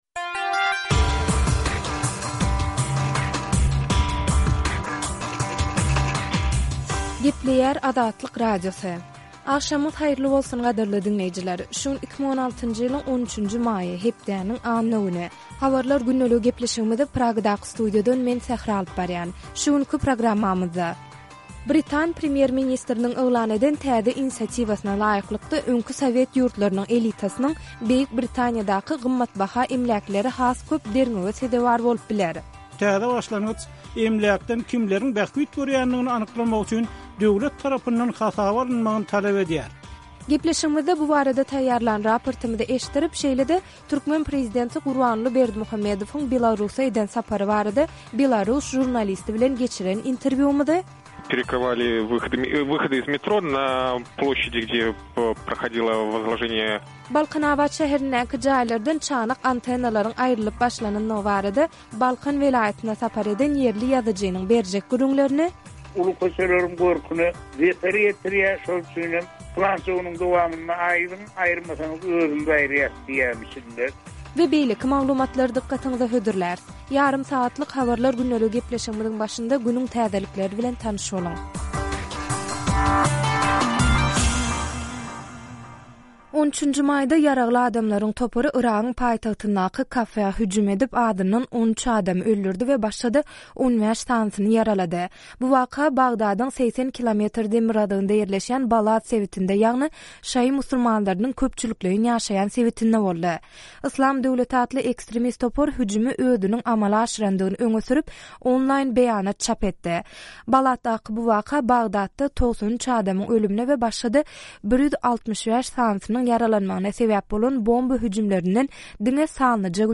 Şu günki programmamyzda: Britan premýer-ministriniň yglan eden täze inisiatiwasyna laýyklykda, öňki sowet ýurtlarynyň elitasynyň Beýik Britaniýadaky gymmatbaha emläkleri has köp derňewe sezewar bolup biler; prezident G.Berdimuhamedowyň Belarusa eden sapary barada belarus žurnalisti bilen geçiren interwýumyzy, Balkanabat şäherindäki jaýlardan çanak antennalary aýyrmak we goşmaça otaglary ýykmak çäresiniň güýçlenendigi barada Balkan welaýatyna sapar eden ýerli ýazyjynyň berjek gürrüňlerini, we beýleki maglumatlary diňläp bilersiňiz.